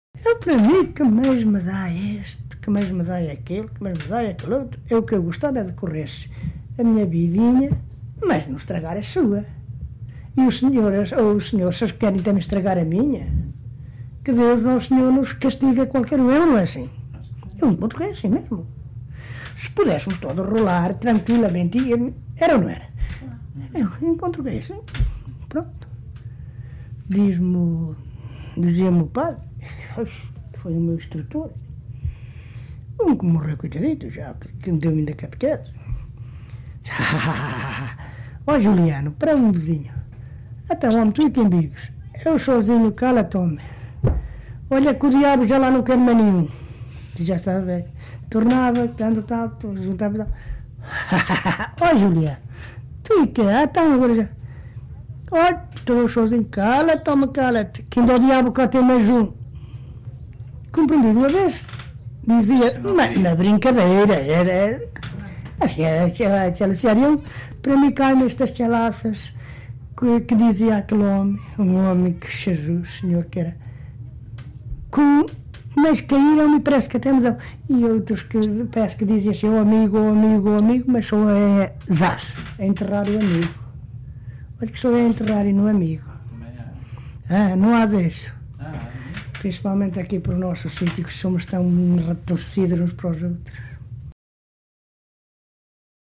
LocalidadeSanto André (Montalegre, Vila Real)